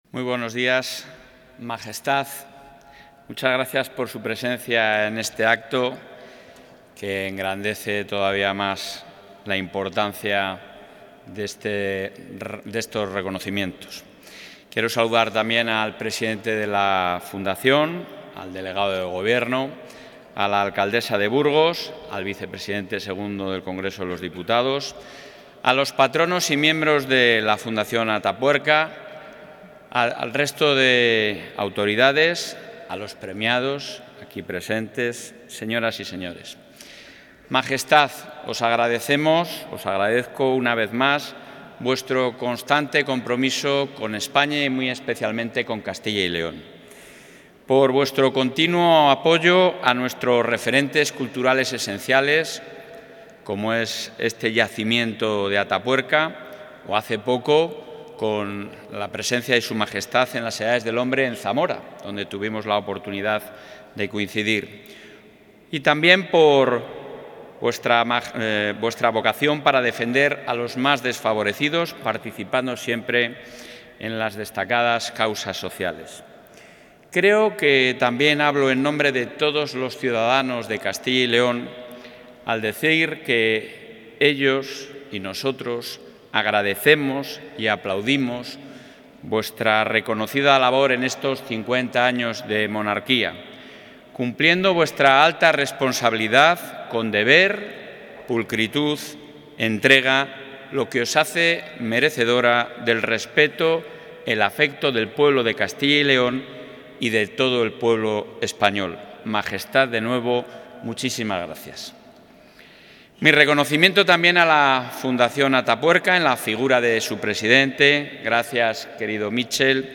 Intervención del presidente de la Junta.
El presidente de la Junta de Castilla y León, Alfonso Fernández Mañueco, ha asistido hoy al acto de entrega de la 'VIII edición Premios Evolución y los I Premios Emiliano Aguirre', organizados por la Fundación Atapuerca y presididos por la Reina Doña Sofía, donde ha agradecido el Premio Evolución a los Valores Humanos que ha recibido de manos de Su Majestad.